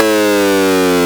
gameover5.wav